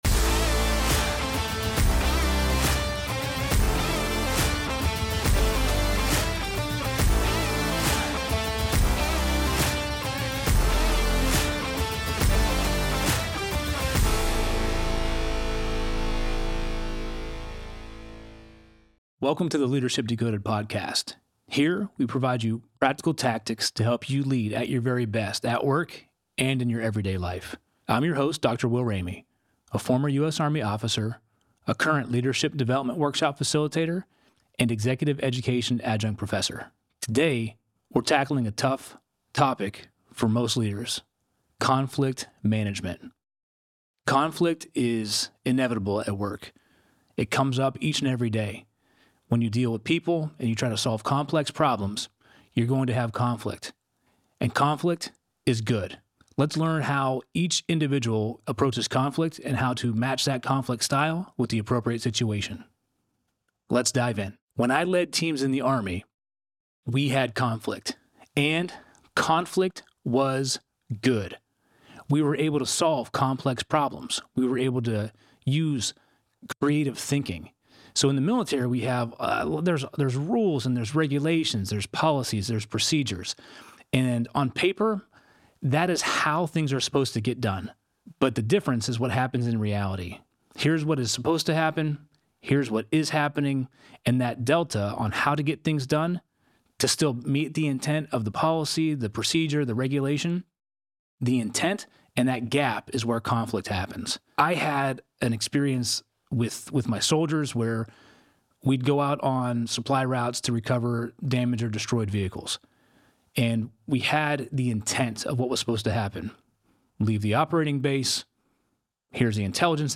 in the Loop Internet studio